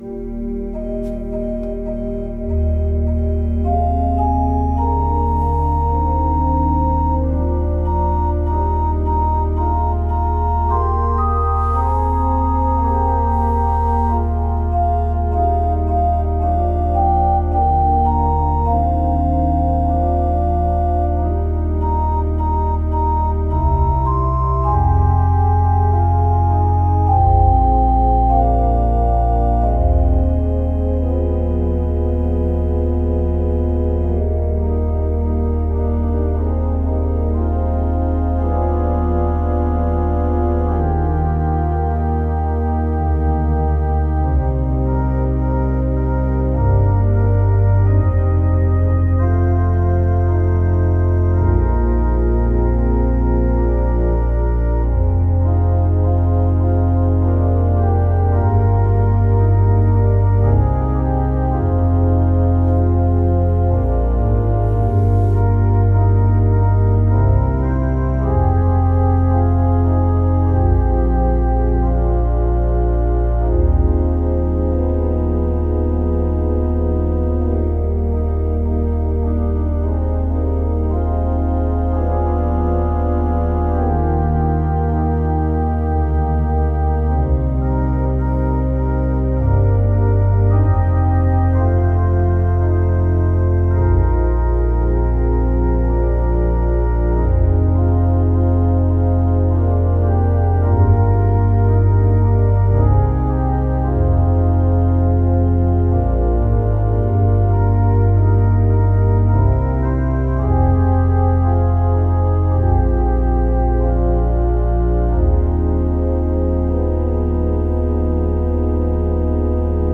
God Loved Us, So He Sent His Son is one of my favorite hymns.